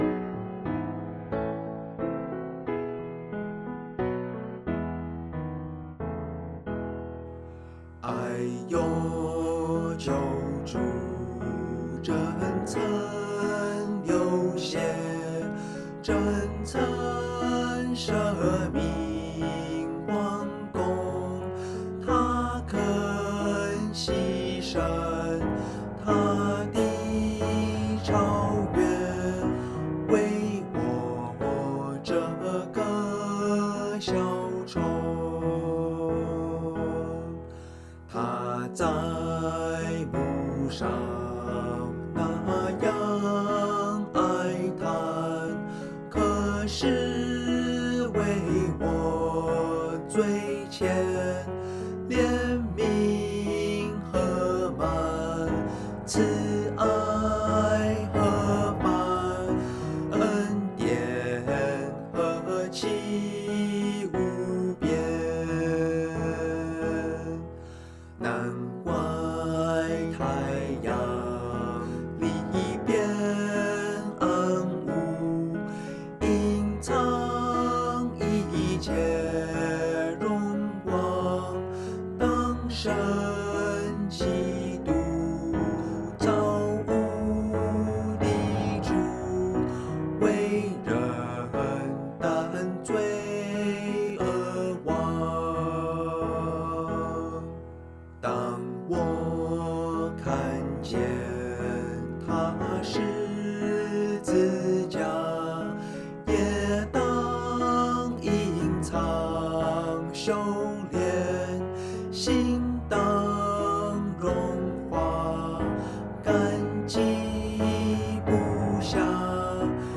ch_0093_vocal.mp3